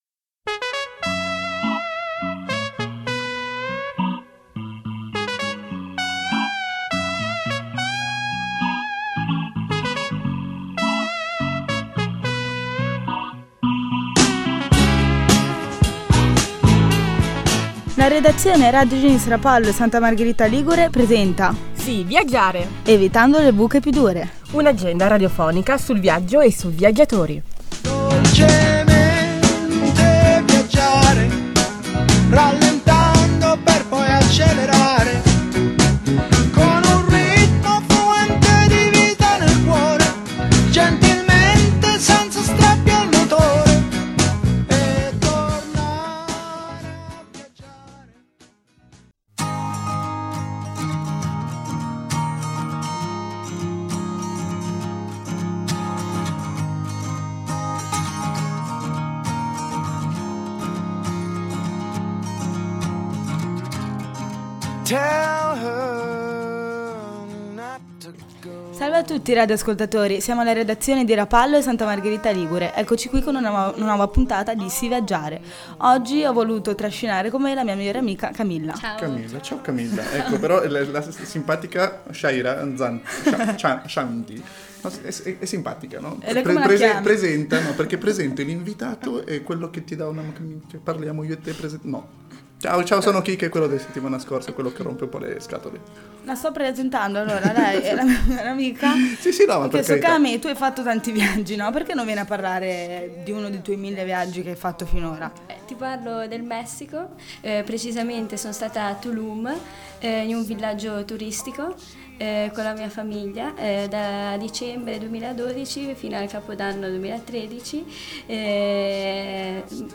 Questo nuovo format è incentrato sul tema del viaggio e del turismo. In questa puntata due ospiti in redazione ci parlano di due mete lontane; il Messico e la capitale del Portogallo Lisbona.